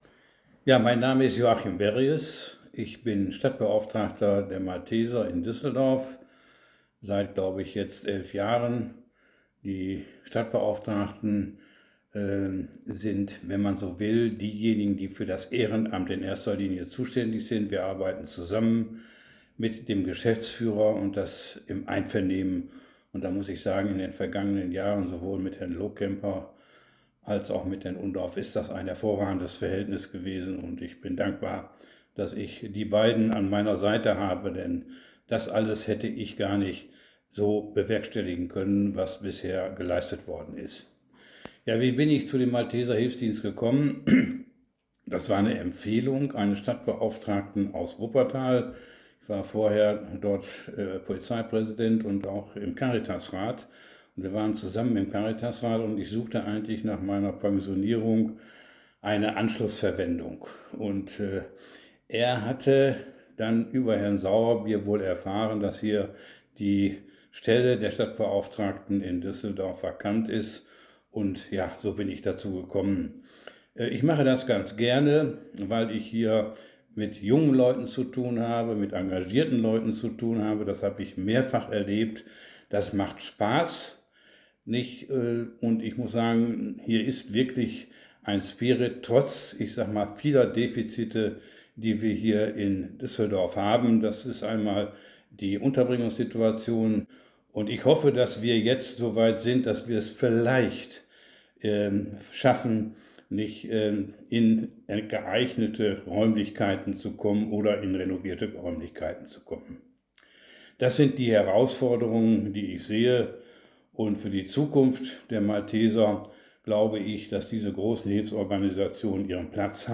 In unserer Audioausstellung erzählen Mitarbeitende und Ehrenamtliche von ihrem Engagement bei den Maltesern in Düsseldorf.